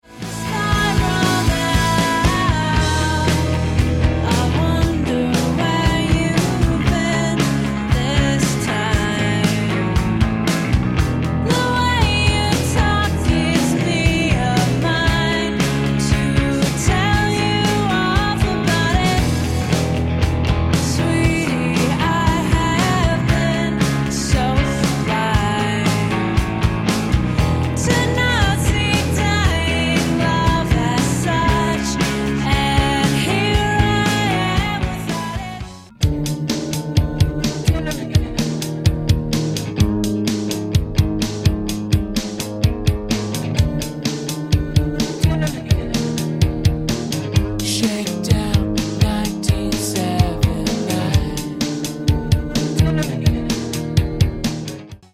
Even the guitar tone is quite similar.